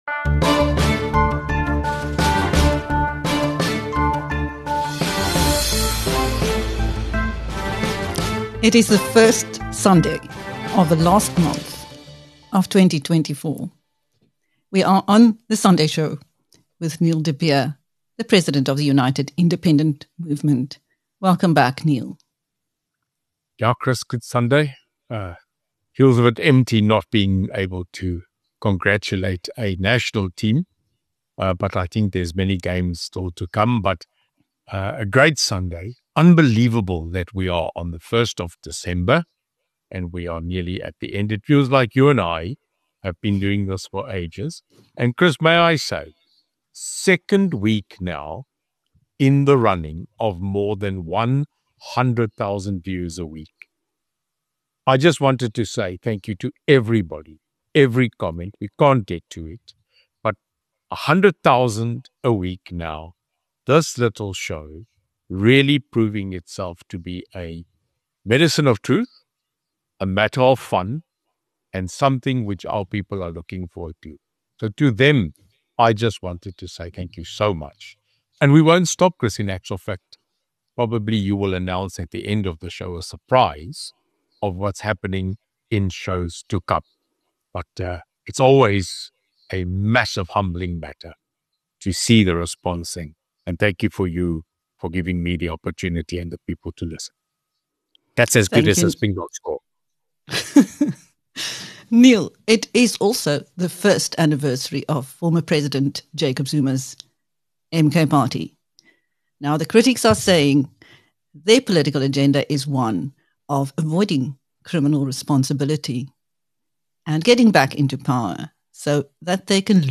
Welcome to BizNews Radio where we interview top thought leaders and business people from South Africa and across the globe.